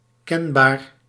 Ääntäminen
IPA : /nəʊn/ US : IPA : /nɔʊn/